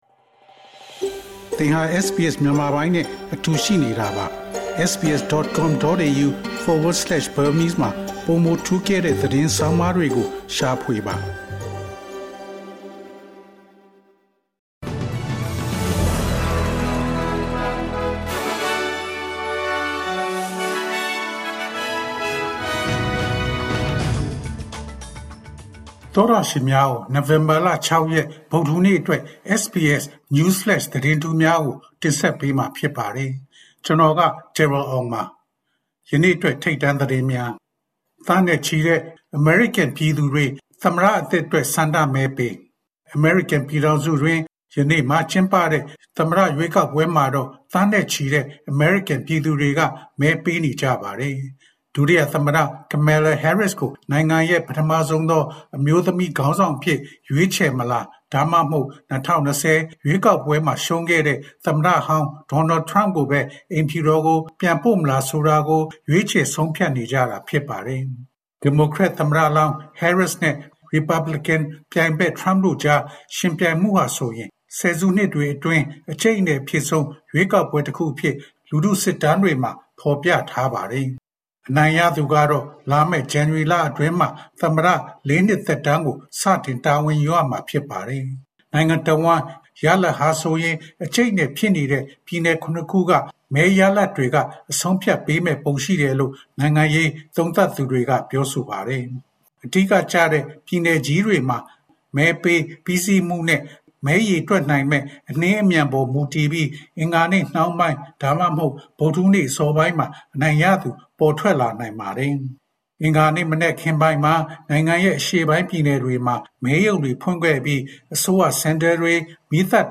ALC: SBS မြန်မာ နိုဝင်ဘာလ ၆ ရက် News Flash သတင်းများ။
SBS မြန်မာ ၂၀၂၄ နှစ် နိုဝင်ဘာလ ၆ ရက် News Flash သတင်းများ။